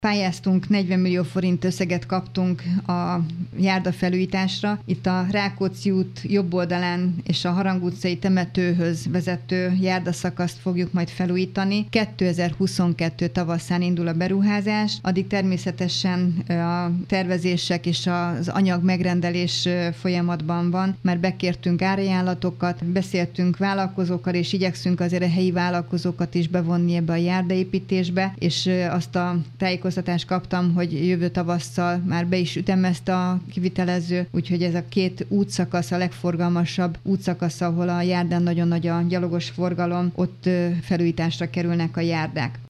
2022-ben indul a két legforgalmasabb járdaszakasz kivitelezése Pilisen. 40 millió forintot nyertek a beruházáshoz, a tervezést már elkezdték. Hajnal Csilla polgármestert hallják.